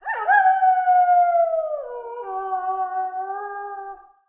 sounds_wolf_howl.ogg